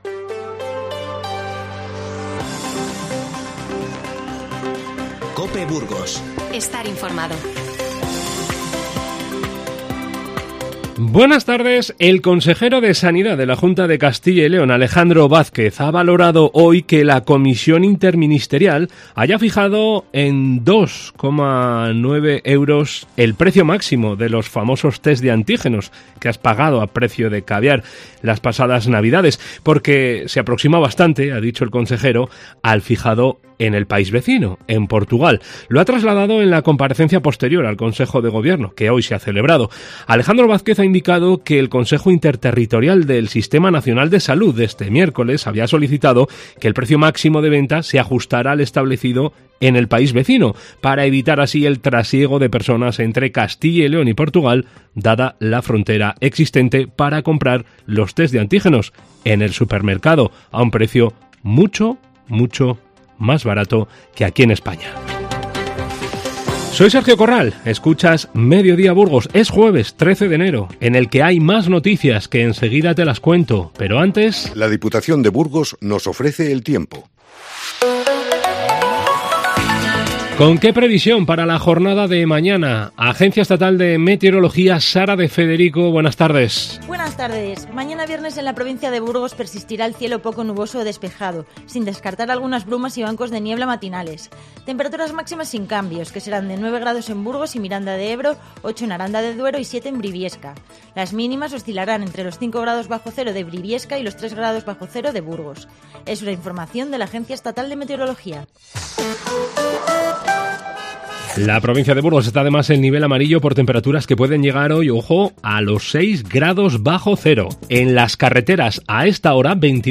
INFORMATIVO Mediodía 13-01-22